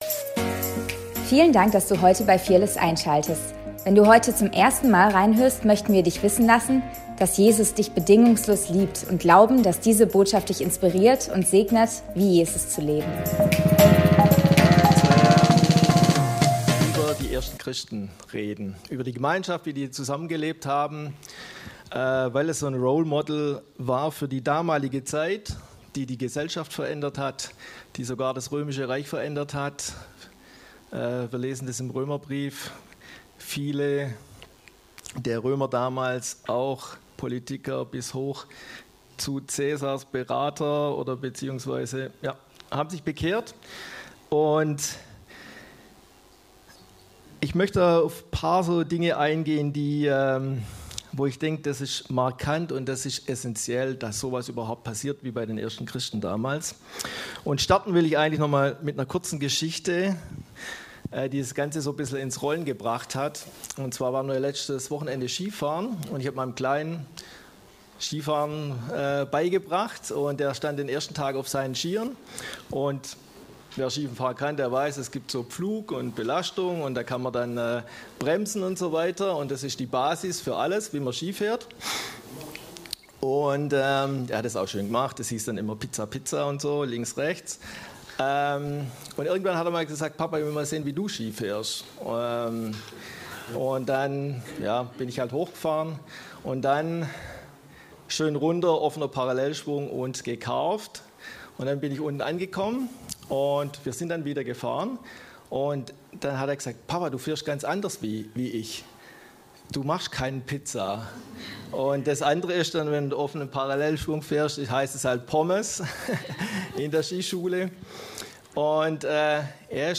Predigt vom 01.02.2026